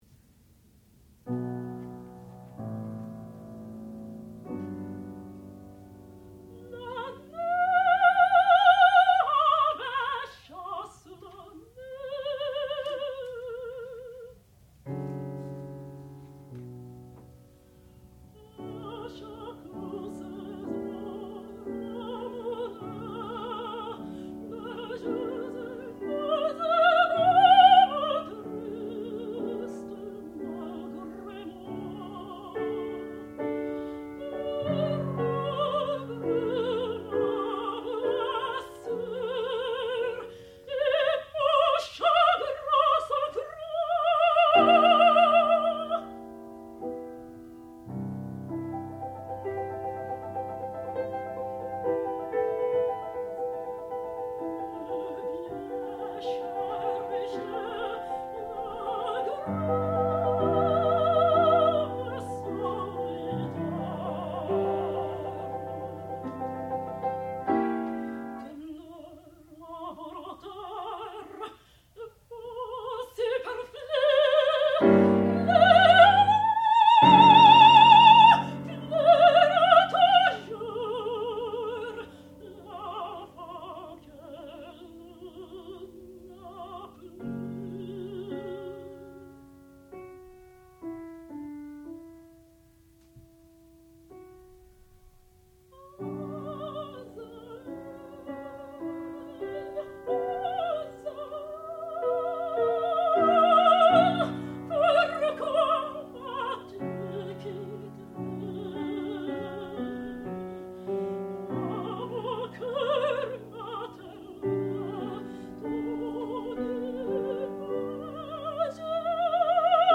sound recording-musical
classical music
piano
soprano
Master's Recital